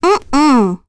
Ophelia-vox-Deny1.wav